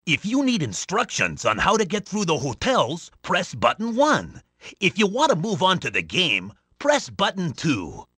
Unused voice sample of Mario in Hotel Mario.
HM_Mario_unused_voice_sample.oga.mp3